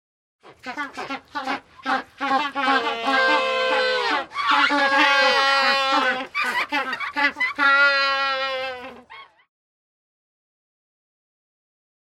animal
Magellanic Penguins Small Group Braying 4